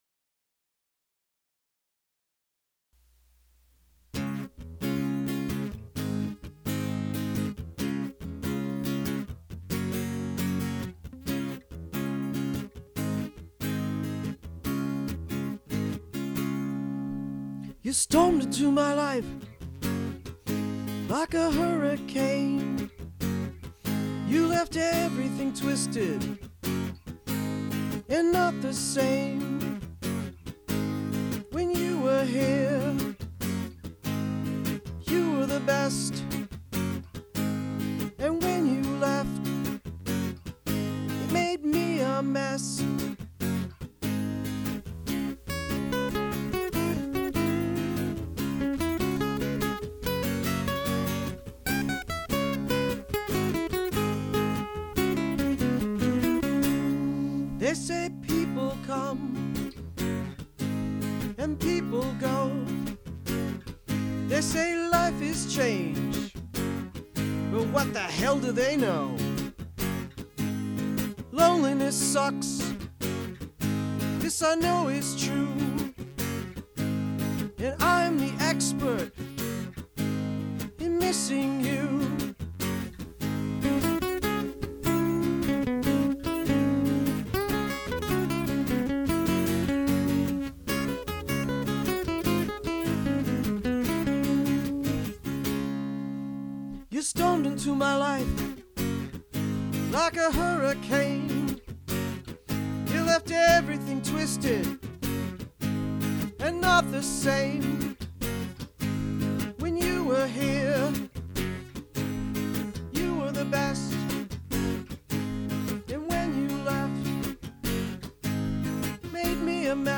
Recorded in Low-Fidelity December 2005-February 2006 at
Guitar
and  Drum Machine